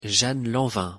живыми носителями языка двух разных диалектов (не бот ИИ).
Прослушать произношение Jeanne Lanvin французский (pronunciation fr) Jeanne Lanvin мужской голос: